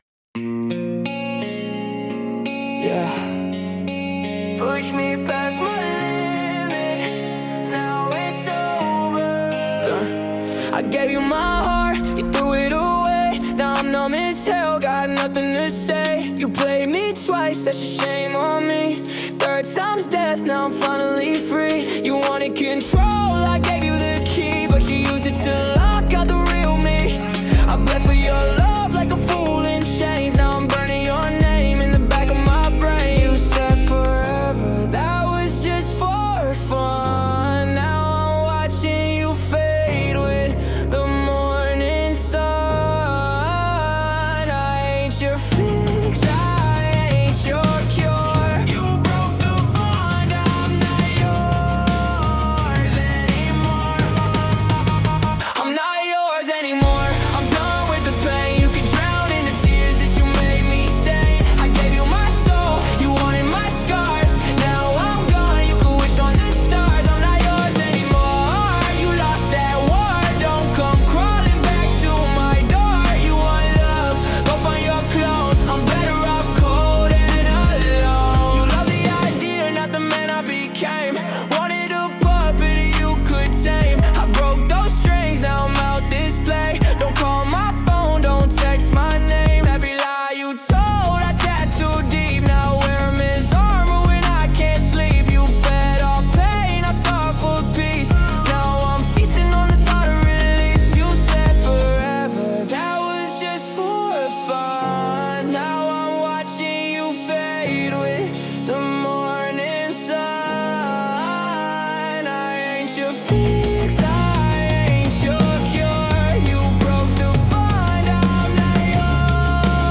Royalty-Free Rap / Pop music track
Genre: Rap / Pop Mood: Wounded